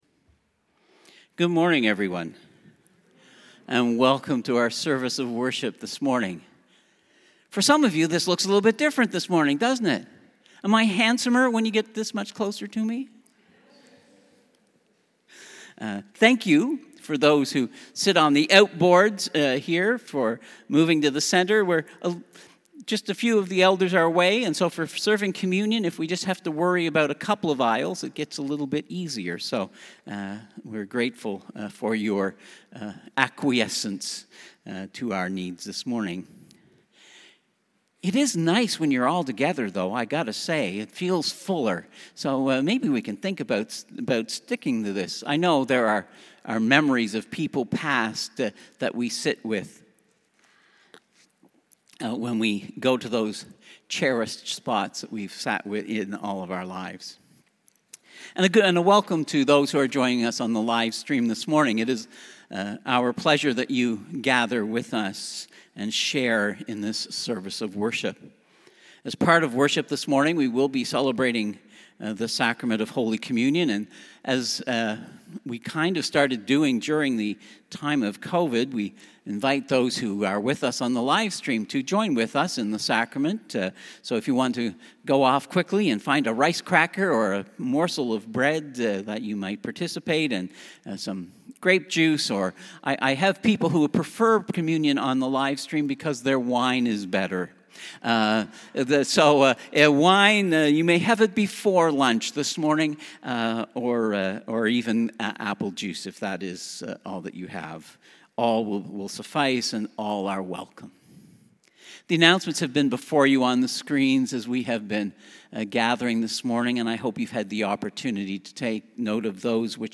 Passage: John 4: 1-15 Service Type: Sunday Service